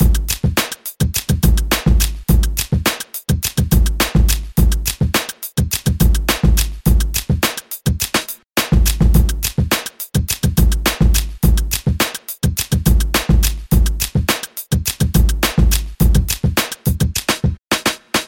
Tag: 105 bpm Rap Loops Drum Loops 3.08 MB wav Key : Unknown